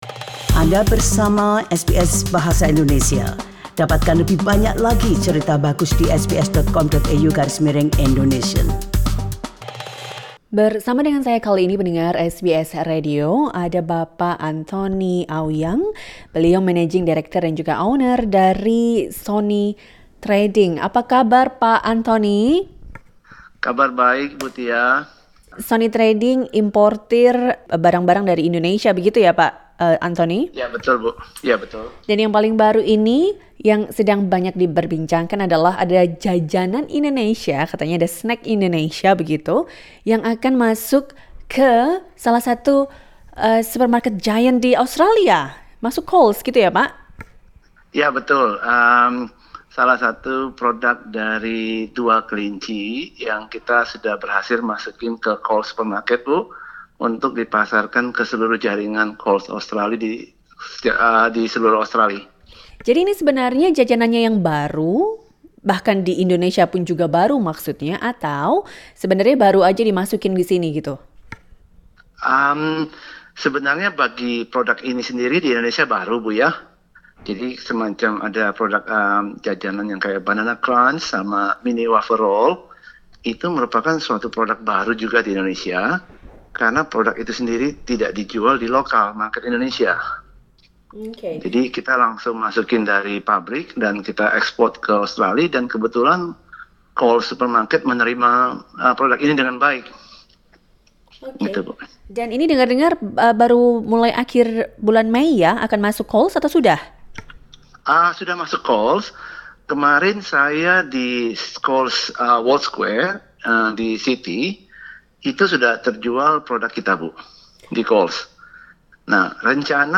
Dengarkan perbincangan selengkapnya di podcast ini.